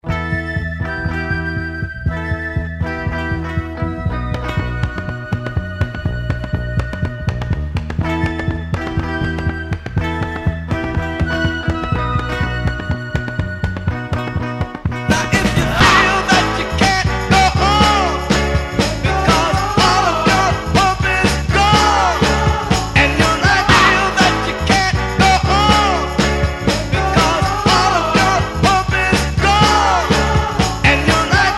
BPM 121